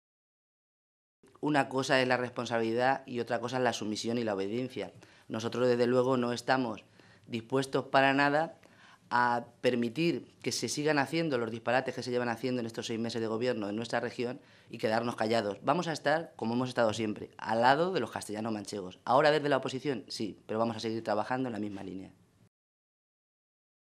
Rosa Melchor, diputada regional del PSOE de Castilla-La Mancha
Cortes de audio de la rueda de prensa